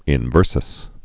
(ĭn-vûrsəs)